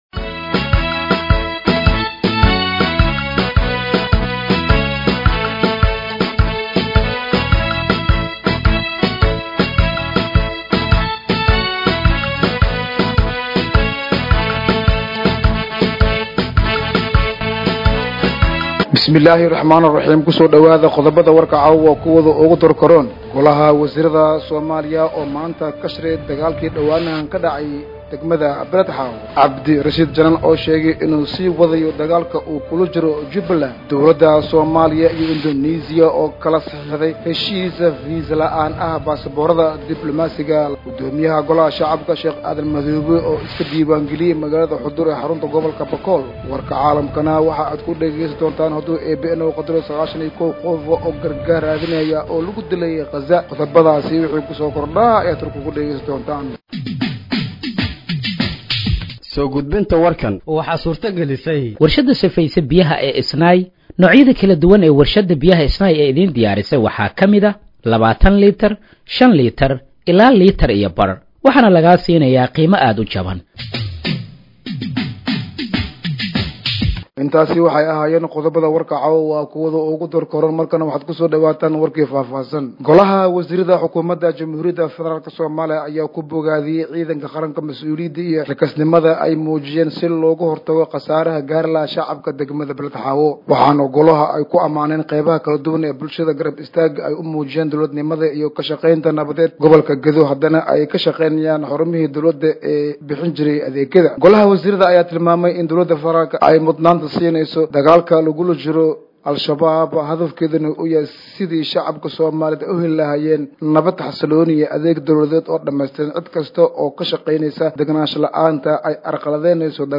Dhageeyso Warka Habeenimo ee Radiojowhar 31/07/2025